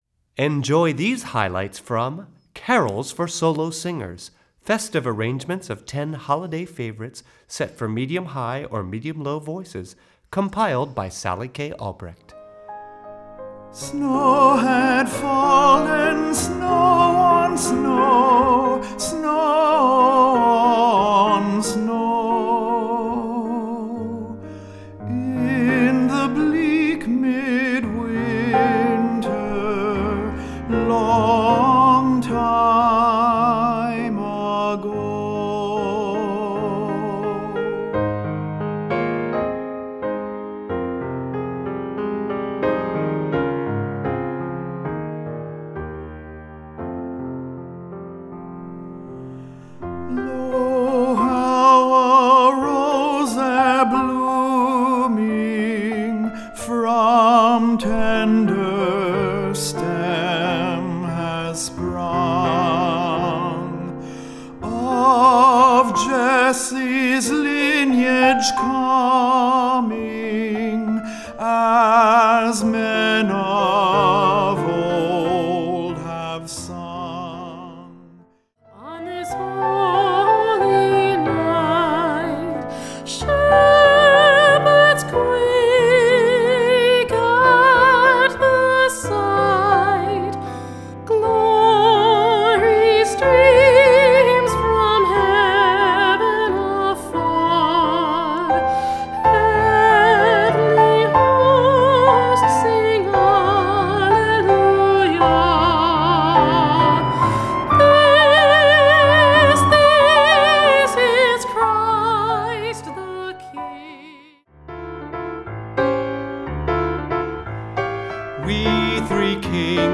Voicing: Medium-Low Voice